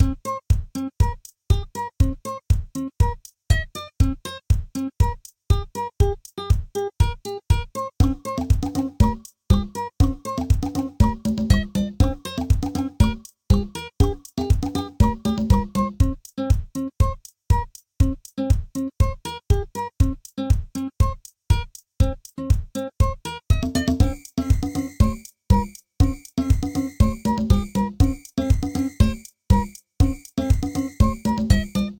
A little looping piece I made for a friend's project.